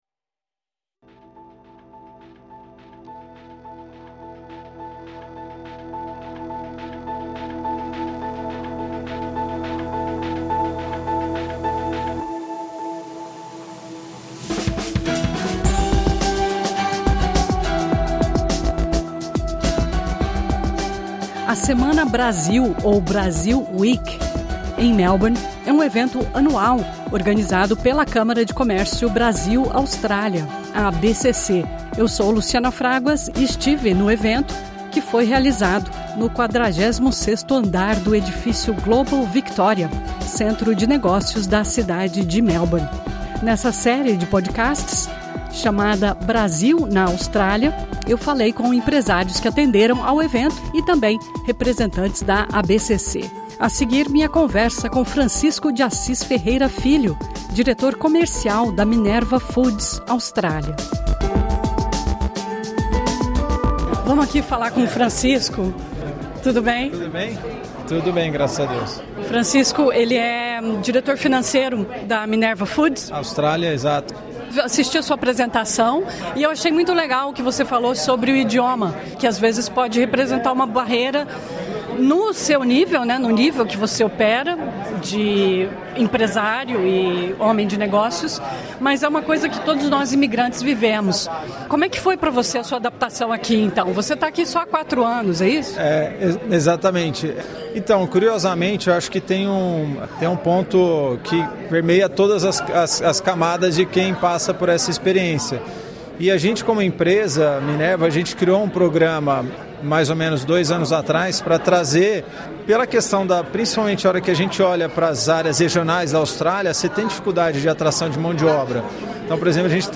Durante evento da Brazil Week em Melbourne